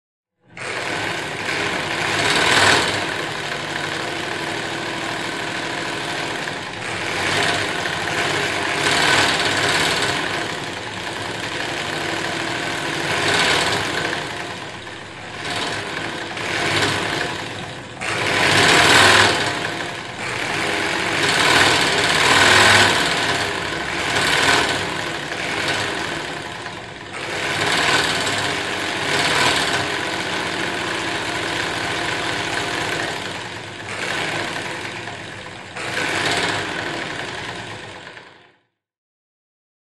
На этой странице собраны реалистичные звуки работы швейной машины.
Звук швейной машинки с педальным приводом